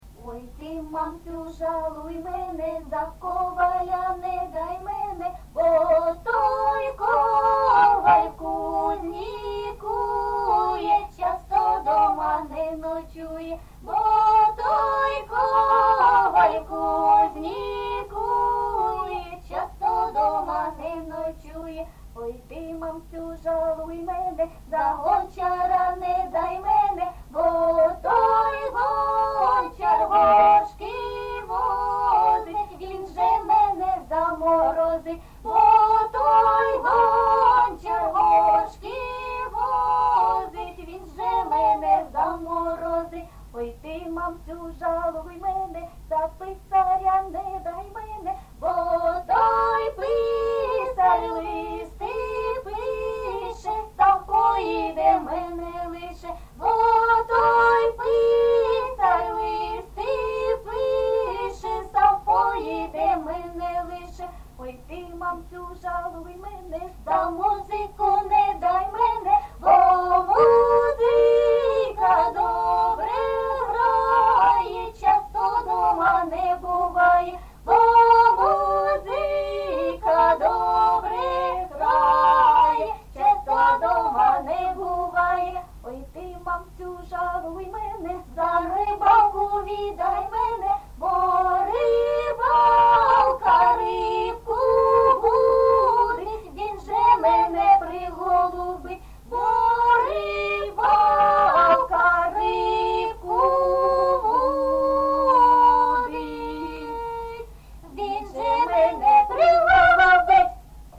ЖанрЖартівливі, Пісні літературного походження
Місце записус-ще Троїцьке, Сватівський район, Луганська обл., Україна, Слобожанщина